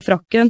Bølgeforma til en uttale av ordet
frakken uten preaspirasjon. (ee)